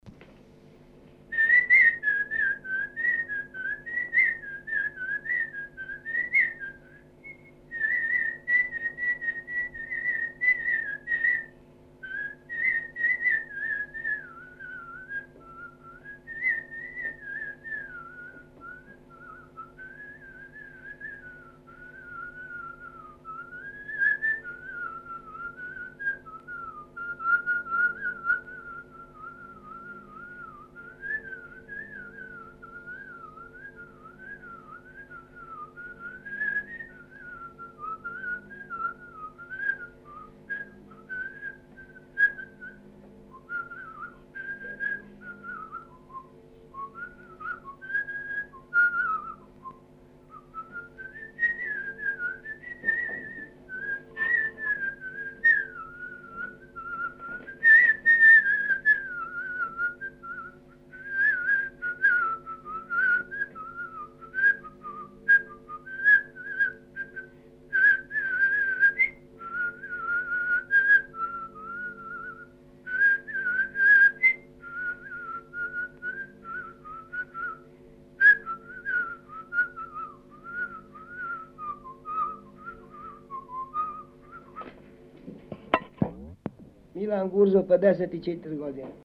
Велико коло из Батање – звиждук
Тема: Инструментална и инструментално-певана музика
Место: Чанад
Напомена: Мелодија традиционалног кола представљена звиждањем.